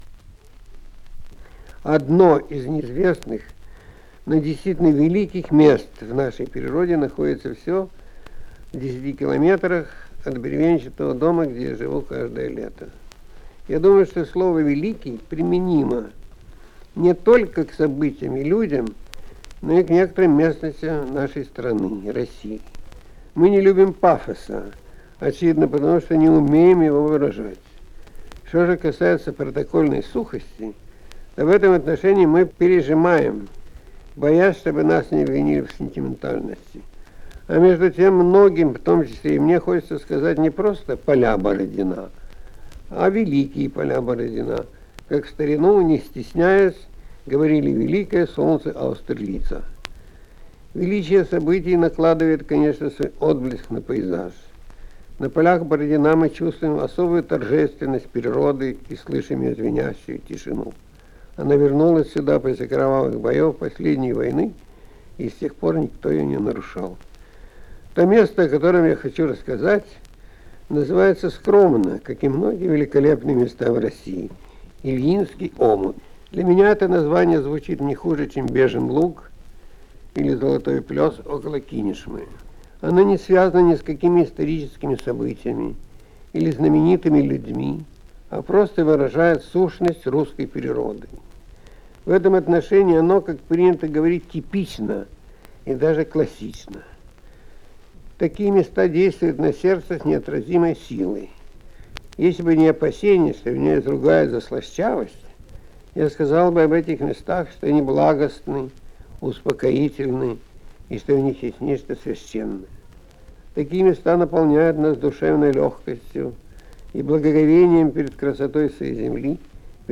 Корреспондент 'Кругозора' попросил Константина Паустовского прочитать для слушателей журнала одно из своих последних произведений.
Звуковая страница 5 - Читает Константин Паустовский.